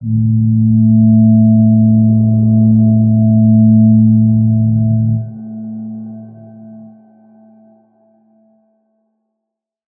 G_Crystal-A3-mf.wav